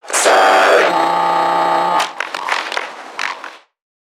NPC_Creatures_Vocalisations_Infected [91].wav